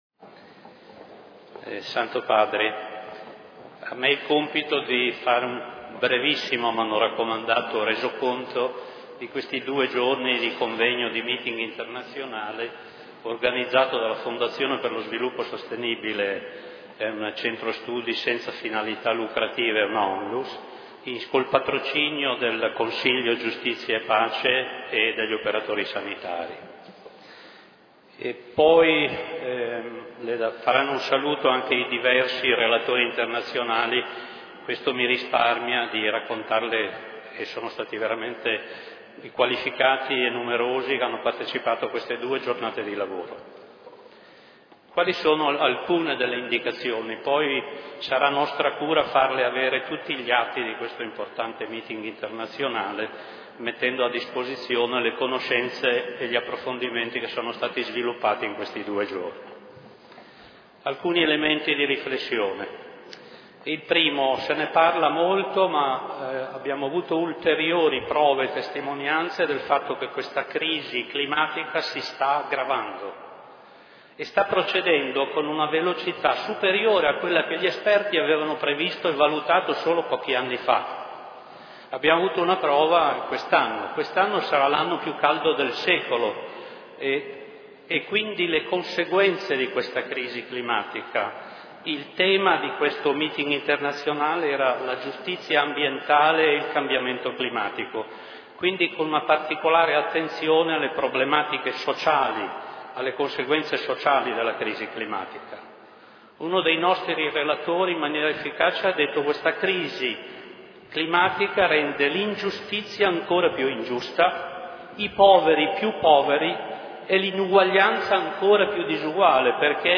Con queste parole il Presidente della Fondazione per lo sviluppo sostenibile, Edo Ronchi,  ha concluso l'11 settembre nella Sala Clementina  del Vaticano la sua presentazione dei risultati della due giorni del  Convegno sulla giustizia ambientale e i cambiamenti climatici (>